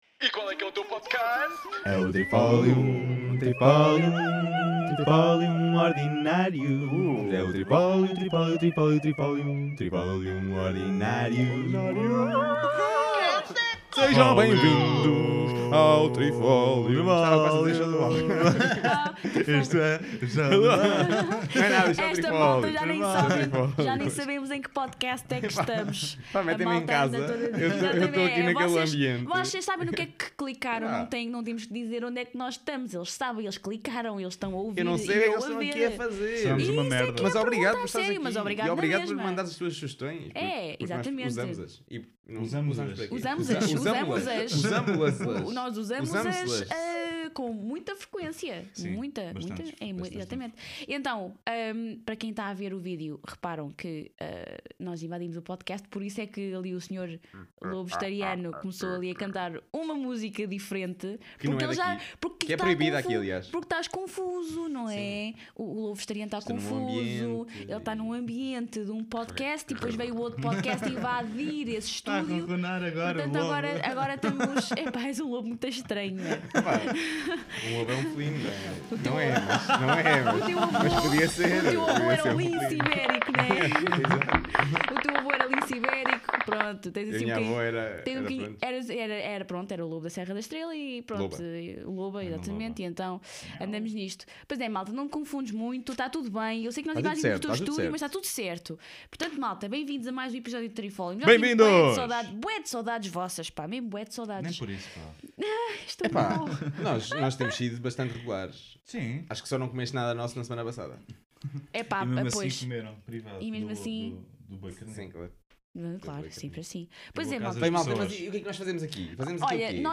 Neste episódio invadimos o estúdio de alguém, pegámos nos seus microfones e falámos dos seguintes temas: 1- Conhecimento 2- Qual é o animal mais útil para o Homem 3- e o jogo desta semana voltou a ser o Trivia Por isso embarca nesta viagem e fica a conhecer alguns dos nossos pontos de vista sobre o que é o conhecimento e o que é o teste do marshmallow (sim esses que se comem).